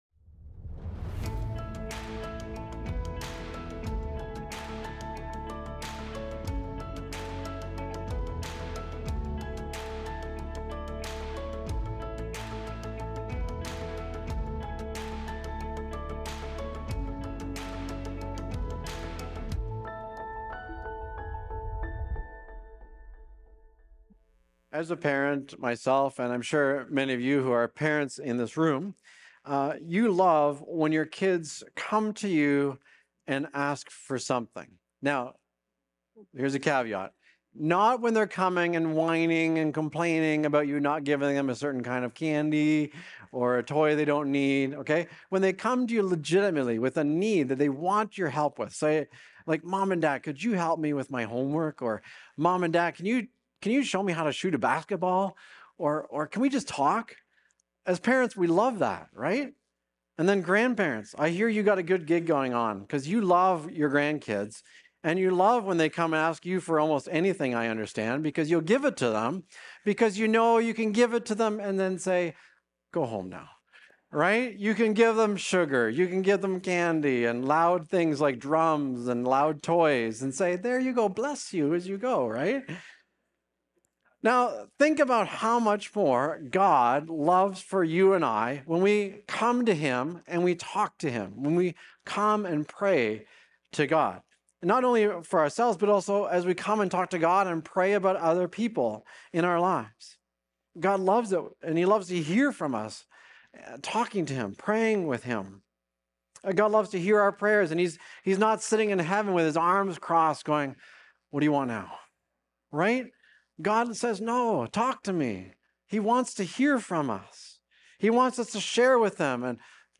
Recorded Sunday, January 11, 2026, at Trentside Fenelon Falls.